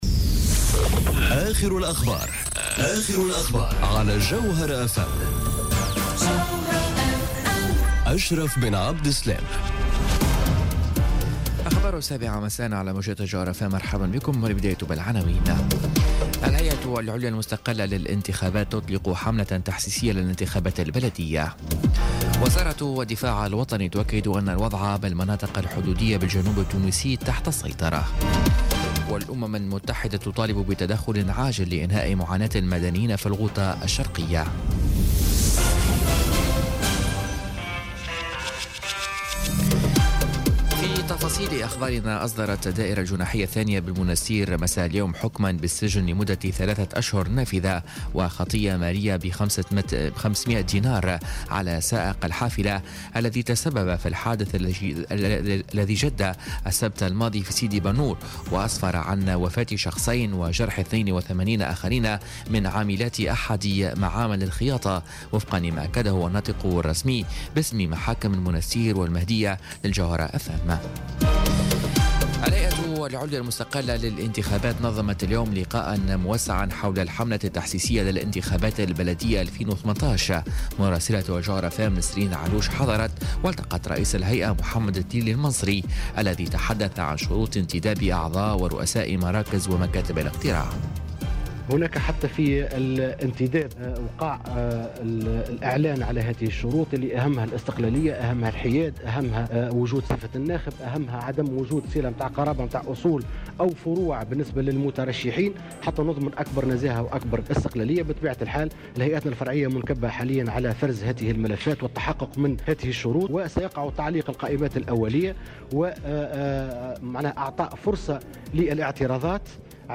نشرة أخبار السابعة مساءً ليوم الثلاثاء 10 أفريل 2018